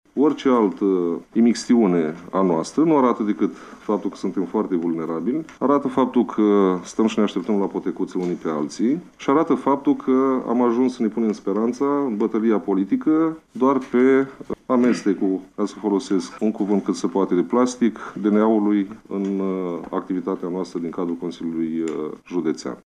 Pe de altă parte, preşedintele grupului social democrat, Cristi Stanciu, i-a îndemnat pe consilierii prezenţi la şedinţă să fie rezervaţi în declaraţii şi să nu dea interpretări politice unor decizii juridice.